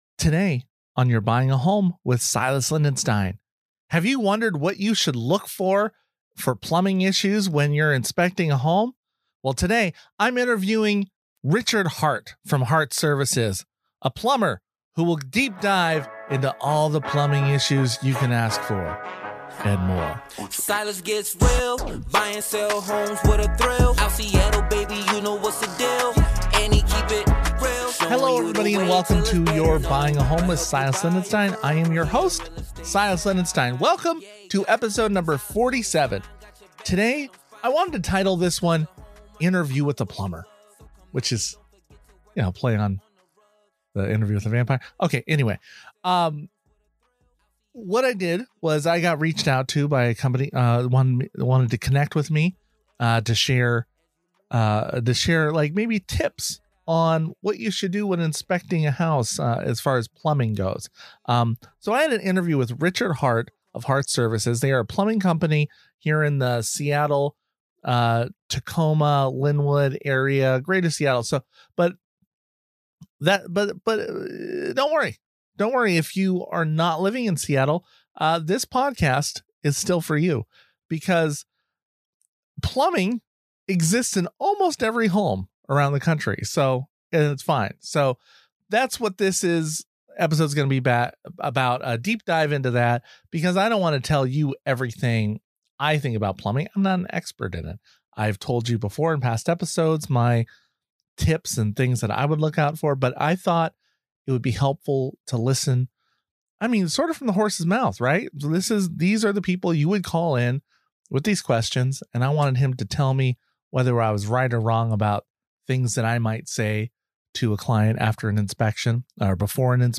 #47: Interview With A Plumber - What To Watch Out For